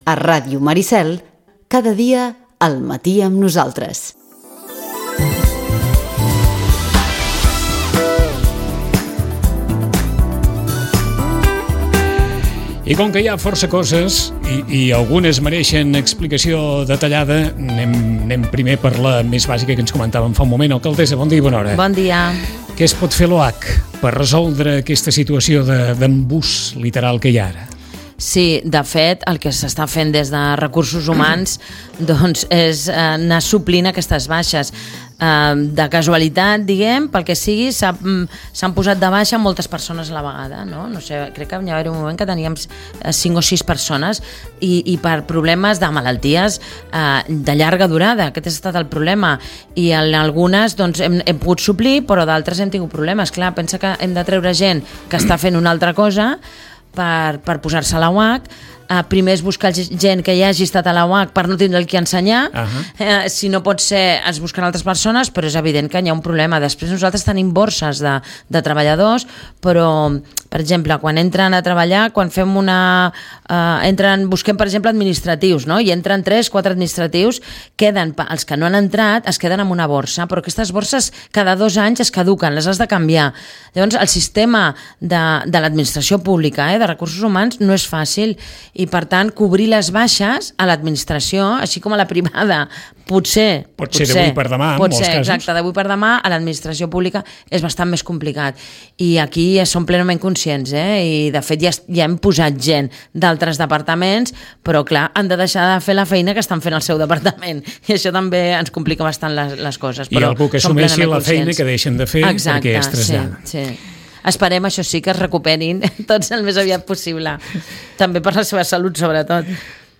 Es tractaria, doncs, d’intentar salvar l’edifici original del Club de Mar, i permetre l’enderroc de les estructures afegides, i a la vegada estudiar la perllongació dels espigons per a poder protegir la platja. La conversa ens ha portat també cap a la futura promoció de 482 habitatges, i a la subhasta de les instal·lacions del Club Natació i la moció sobre la seva municipalització, que l’oposició guanyà en el darrer ple.